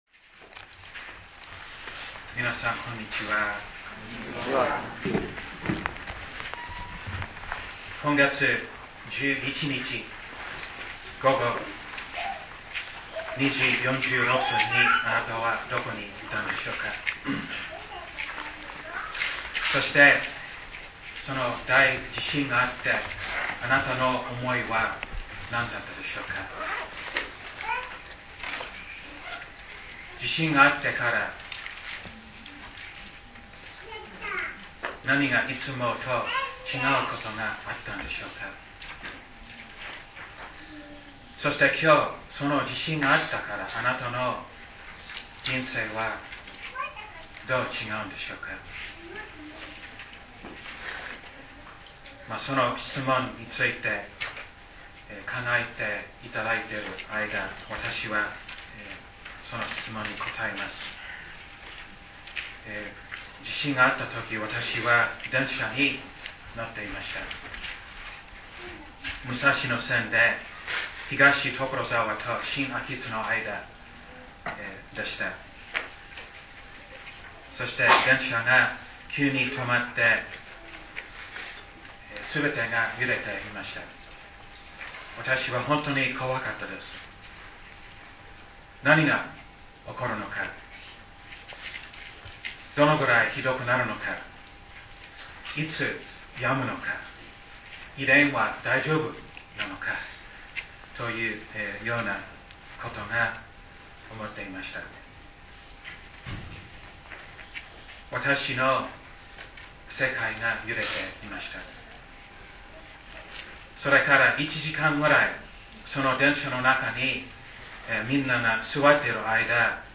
礼拝宣教録音－恐れた中